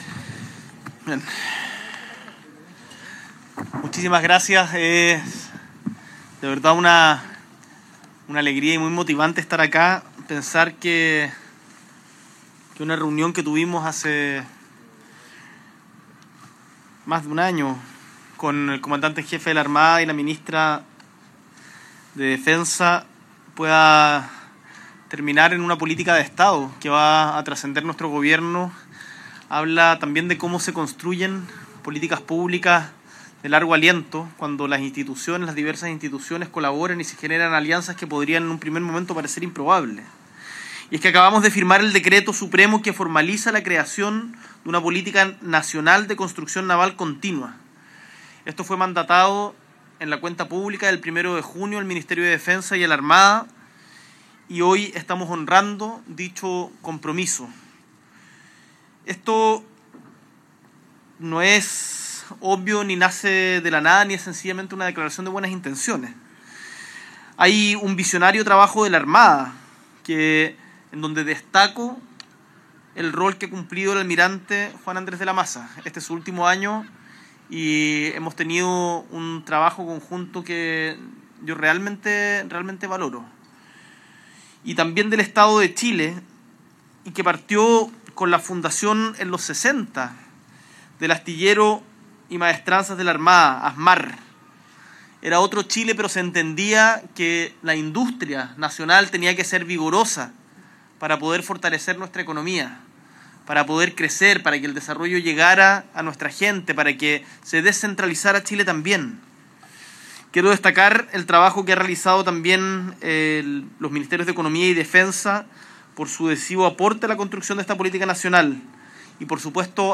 S.E. el Presidente de la República, Gabriel Boric Font, encabeza el lanzamiento del Plan Nacional Continuo de Construcción Naval
Discurso